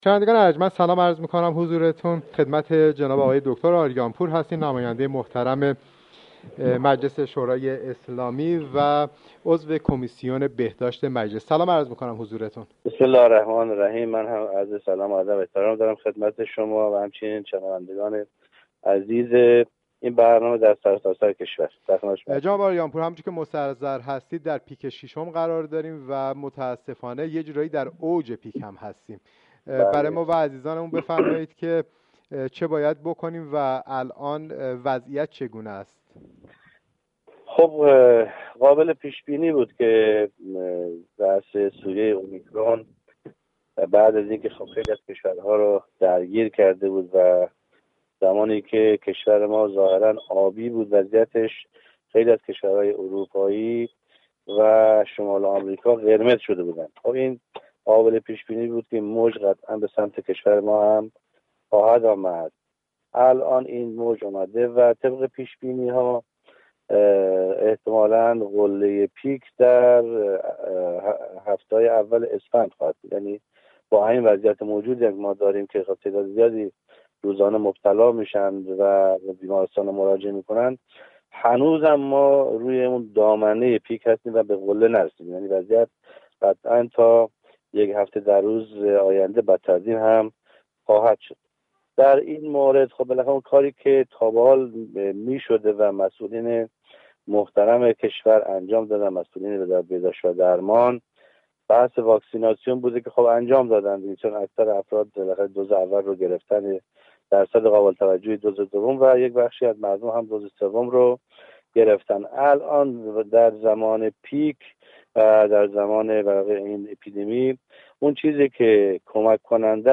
گفت وگوی اختصاصی